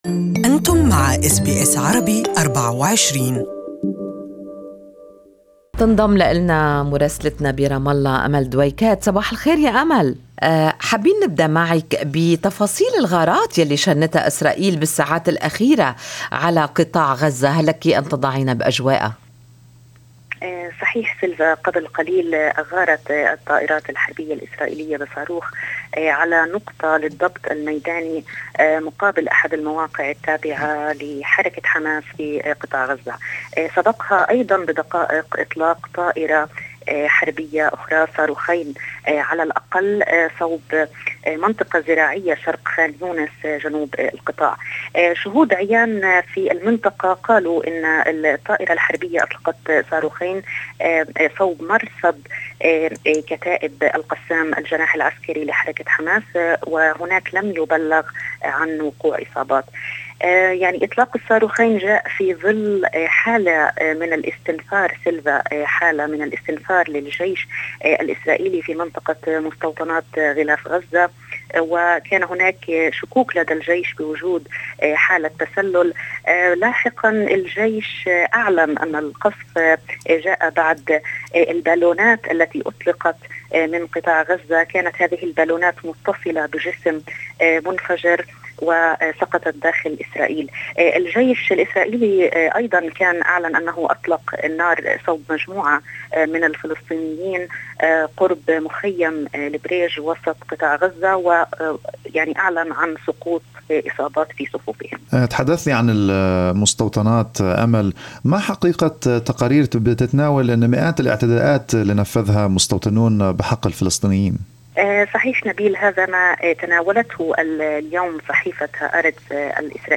Palestine celebrated their first point in AFC Asian Cup history after holding Syria to a 0-0 draw in Sharjah despite playing the final 22 minutes with 10 men. Listen to the full report from our correspondent in Arabic in the audio above.